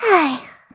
Taily-Vox_Sigh_kr.wav